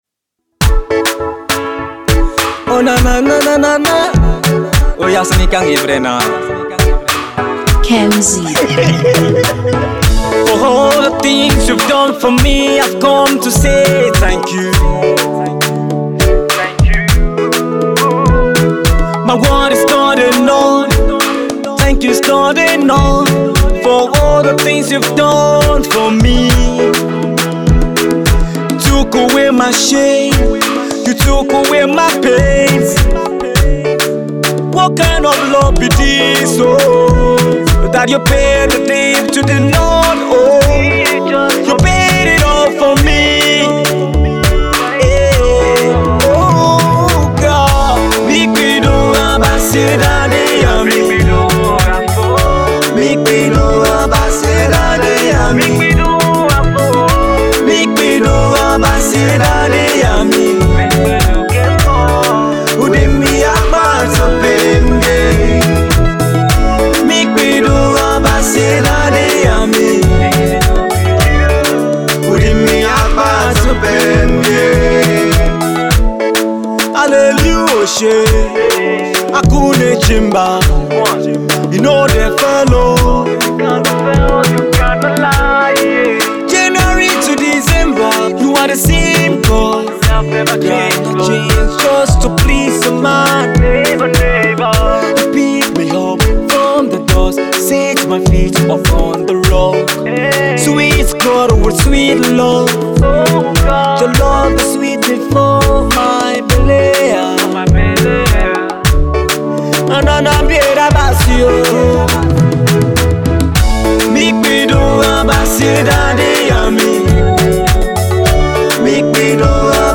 The melodious praise singer
Gospel song